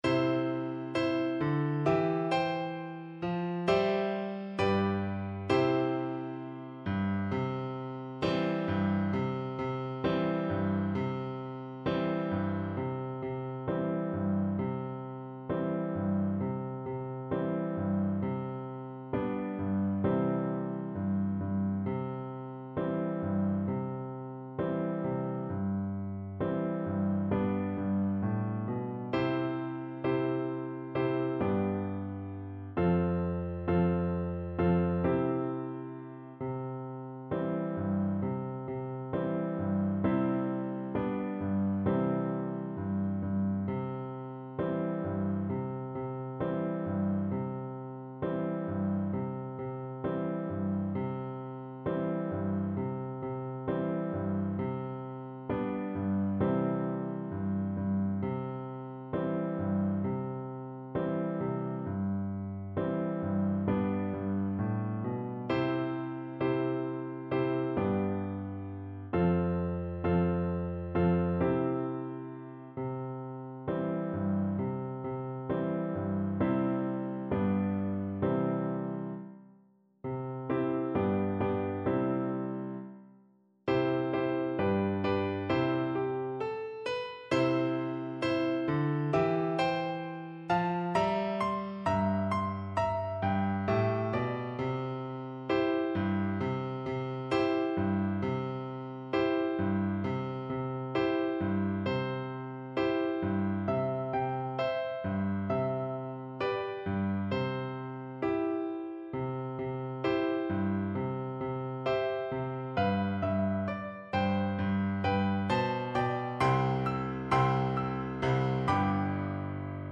World Africa Nigeria L'abe igi orombo
French Horn
C major (Sounding Pitch) G major (French Horn in F) (View more C major Music for French Horn )
Joyfully =c.100
4/4 (View more 4/4 Music)
labe_igi_orombo_HN_kar3.mp3